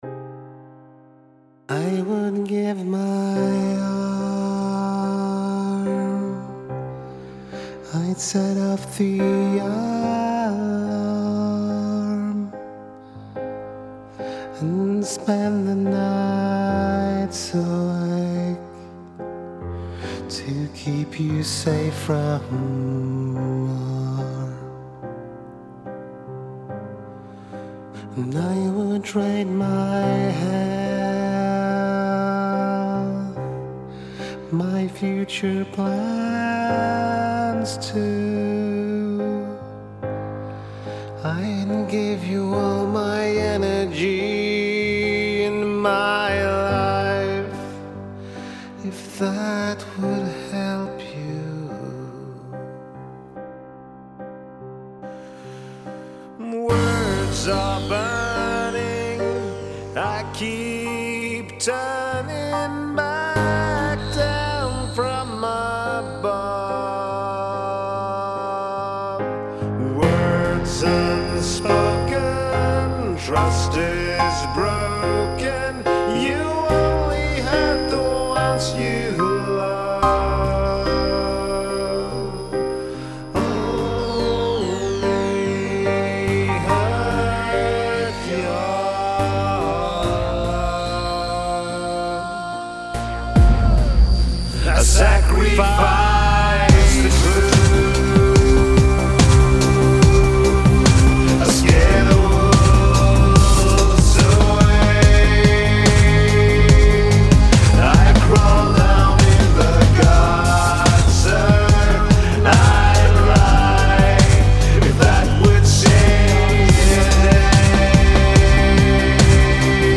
Jag har förhoppningar att kunna sätta all sång bra sedan, nu skaver det lite väl när jag kommer igång.
Hur funkar strukturen? jag försöker att få lite mild introduktion och sedan lite bombom och frustration :)
En helshysst ljudbild, mäktiga ljud från synthen (men inte för mäktiga utan passande till musiken)
Och så fruktansvärt tungt det låter om instrumenten (främst tänker jag på trummorna och basen).
Kul att det går hem med trummor och bas, det skall vara lite boombom.